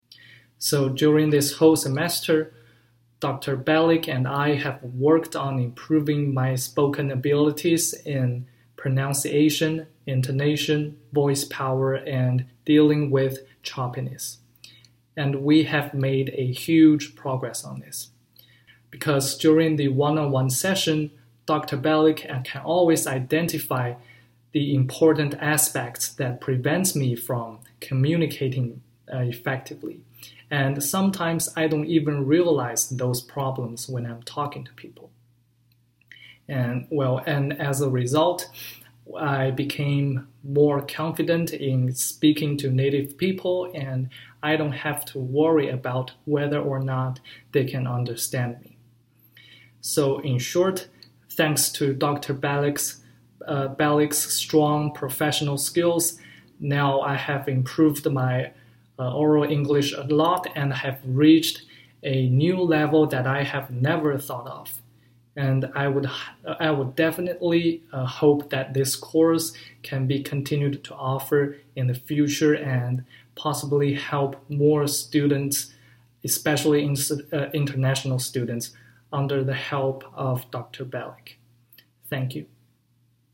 Testimonial 1